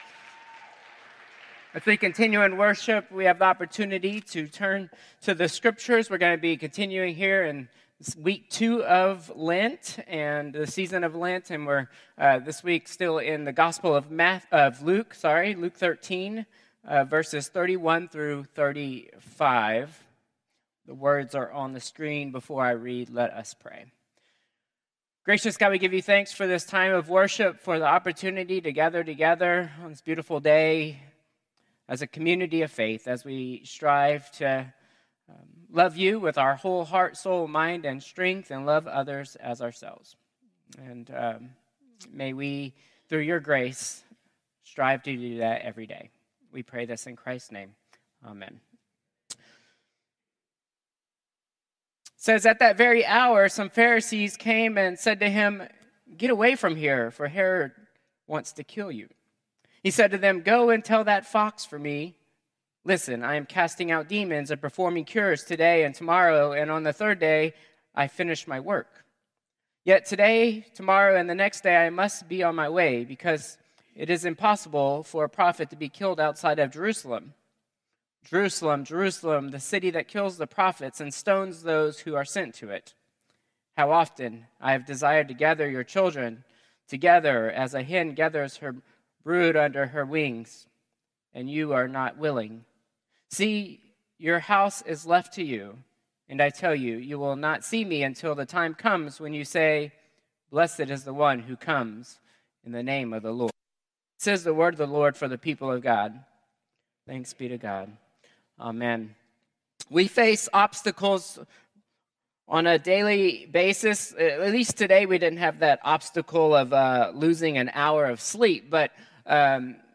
Contemporary Service 3/16/2025